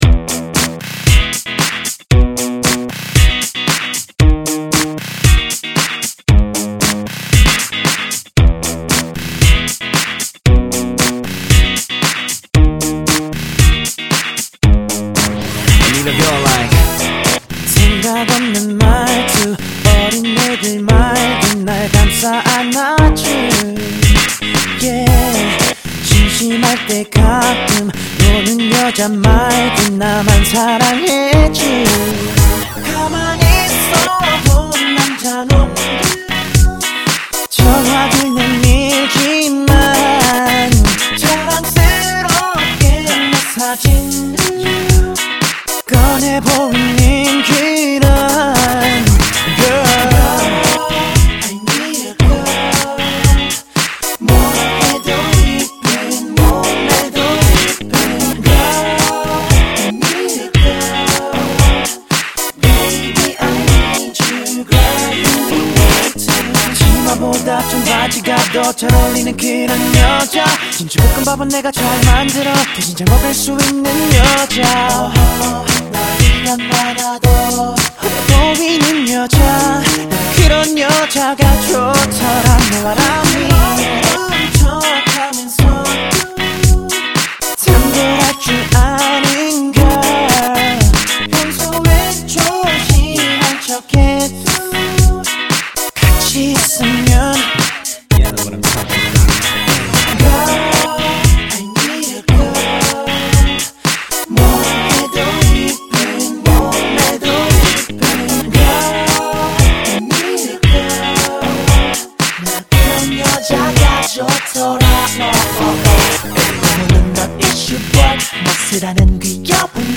• [국내 / REMIX.]
목소리가 좀 작다고 느꼈는데
의도한거 아니고 믹싱 실수 맞습니다